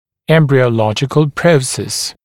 [ˌembrɪə(u)’lɔʤɪkl ‘prəuses] [ˌэмбрио(у)’лоджикл ‘проусэс] эмбриологический отросток